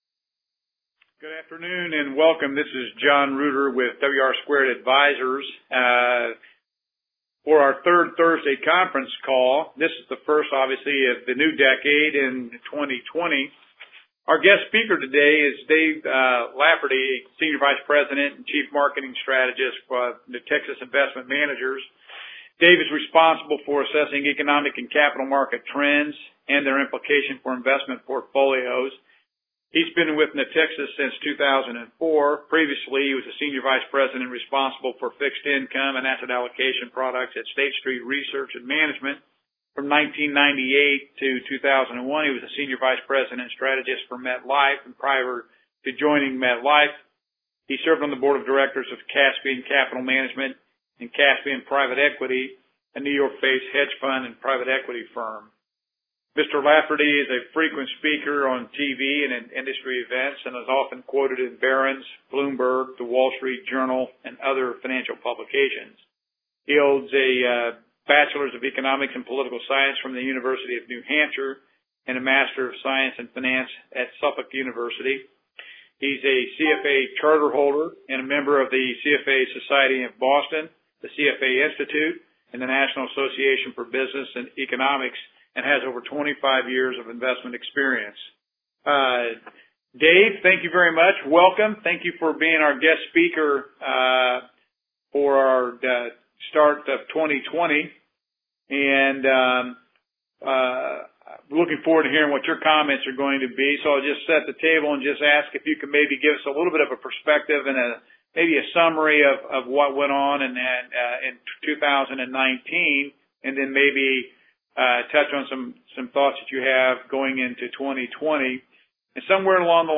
Third Thursday Conference Call January
third-thursday-conference-call.mp3